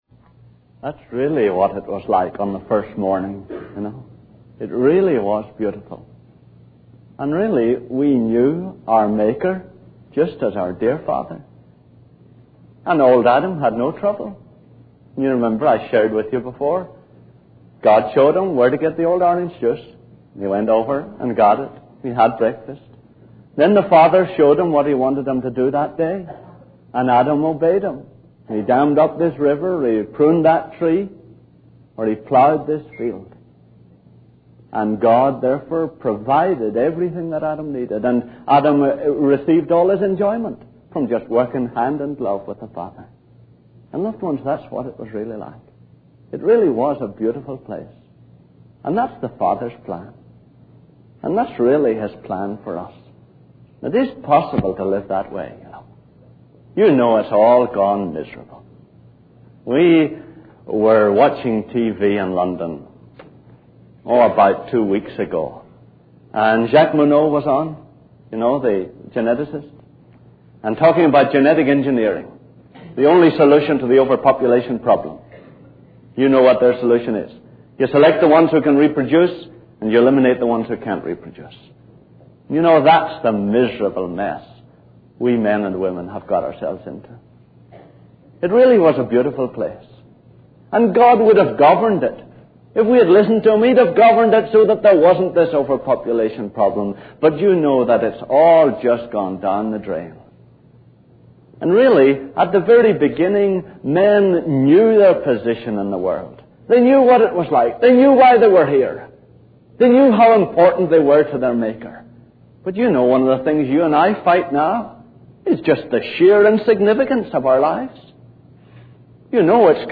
In this sermon, the speaker discusses the issue of overpopulation and genetic engineering as a proposed solution. He reflects on how humanity has strayed from God's original plan for the world and the significance of human life.